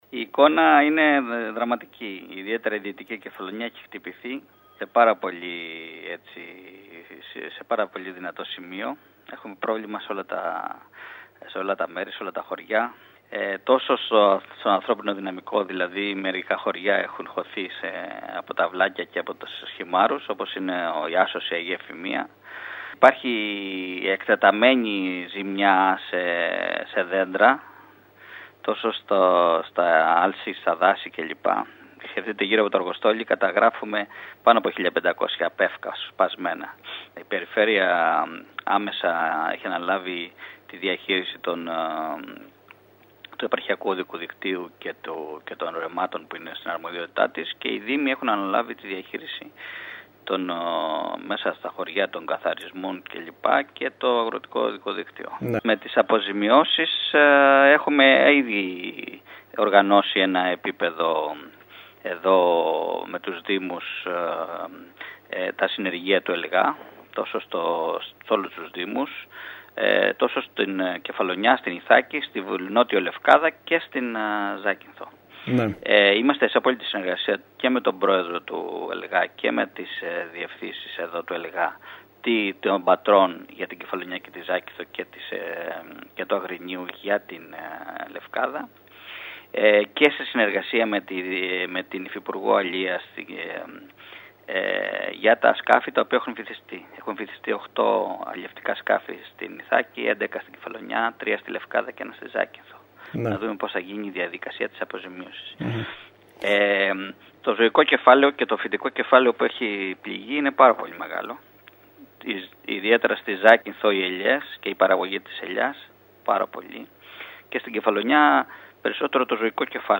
Εγρήγορση υπάρχει και στην Κεφαλλονιά όπου η έλευση ενός δεύτερου κύματος κακοκαιρίας μπορεί να επιβαρύνει ακόμη περισσότερο την κατάσταση στο δοκιμαζόμενο νησί από την προχθεσινή έλευση του Ιανού. Ο αντιπεριφερειάρχης Σωτήρης Κουρής μιλώντας στο σταθμό μας μετέφερε μελανές εικόνες για την κατάσταση που εξακολουθεί να επικρατεί κατά κύριο λόγο στη δυτική Κεφαλλονιά, παρά το γεγονός ότι έχουν ξεκινήσει παρεμβάσεις αποκατάστασης.